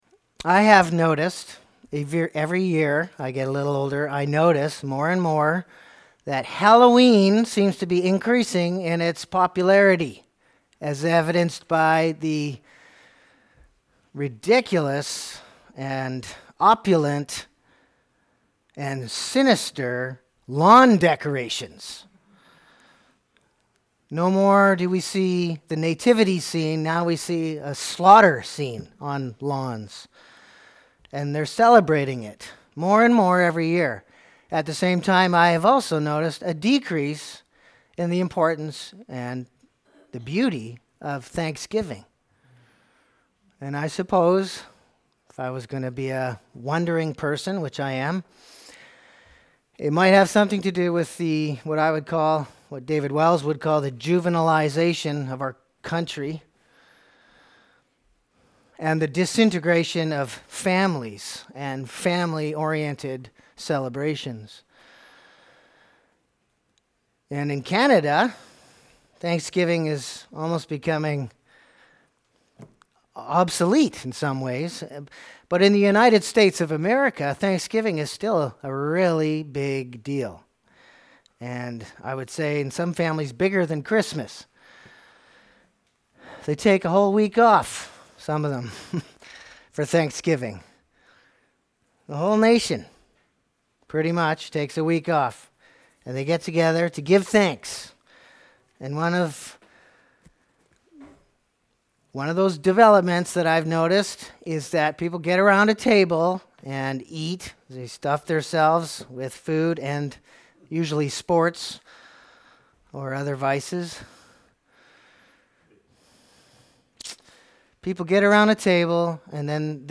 Single Sermons